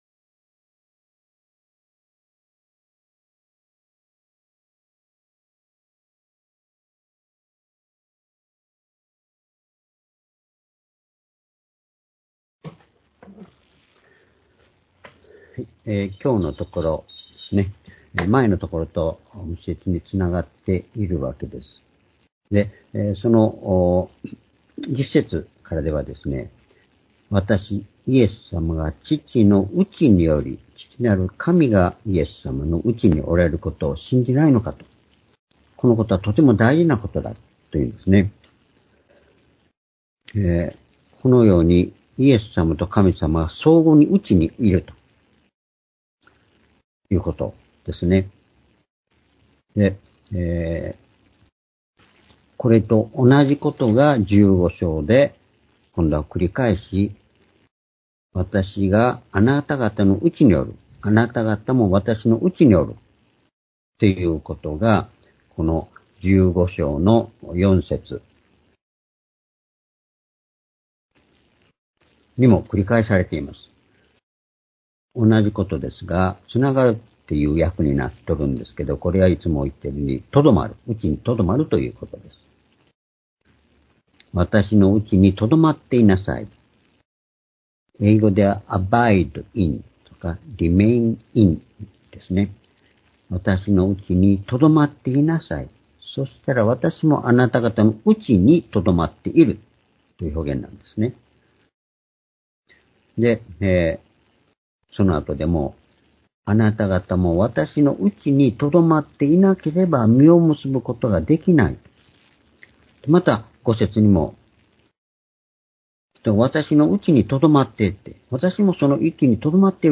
主日礼拝日時 ２０２４年3月10日（主日礼拝) 聖書講話箇所 「主の名によって」 ヨハネ１４の１０－１４ ※視聴できない場合は をクリックしてください。